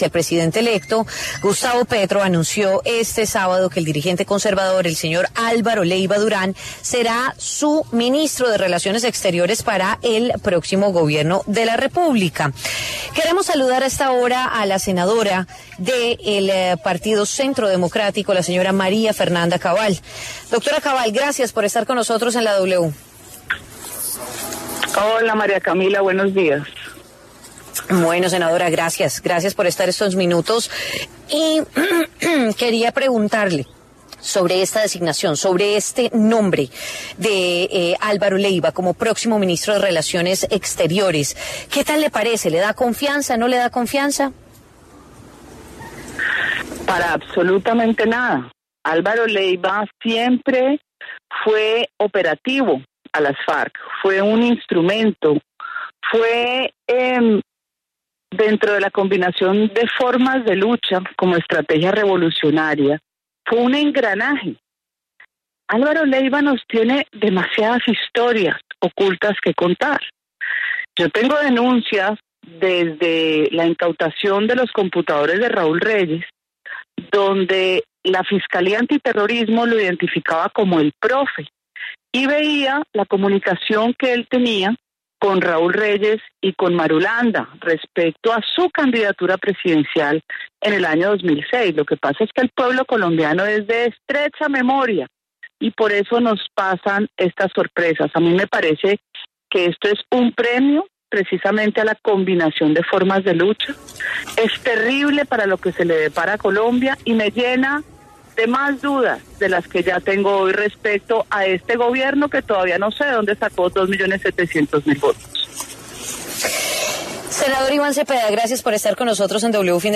María Fernanda Cabal, senadora y Iván Cepeda, senador, hablan en W Fin de Semana sobre dicho nombramiento.